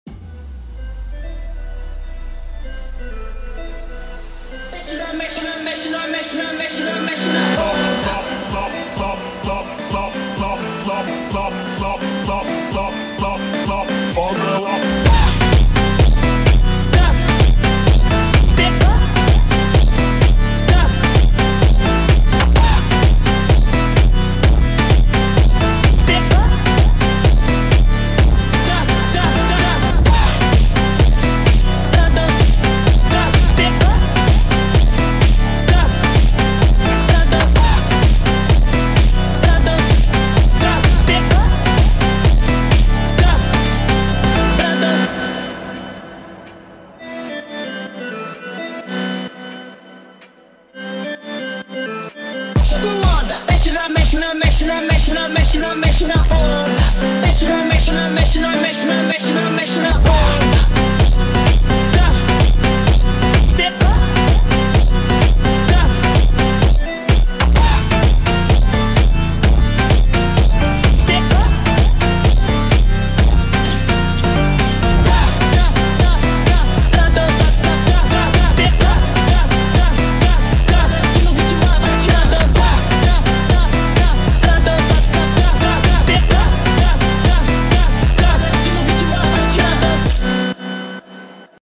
PHONK MUSIC!